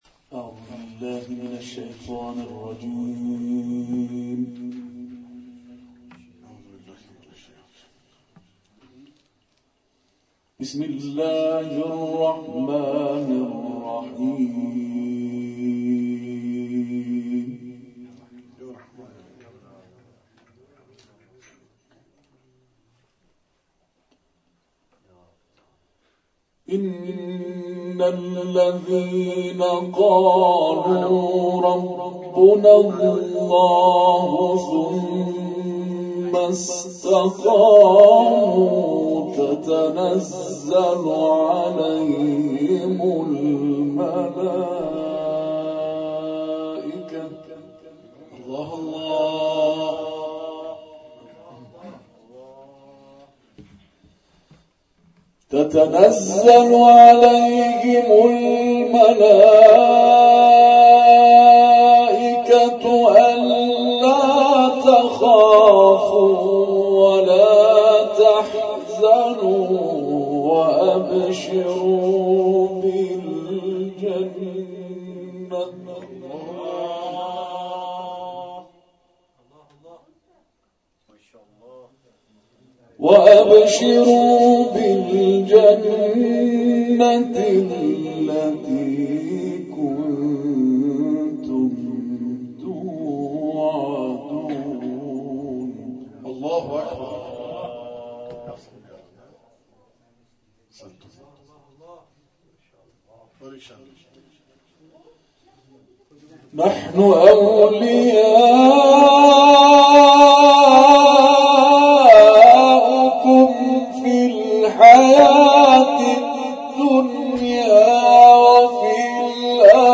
به تلاوت مجلسی با استفاده از قرائات سبعة پرداخت که در ادامه ارائه می‌شود.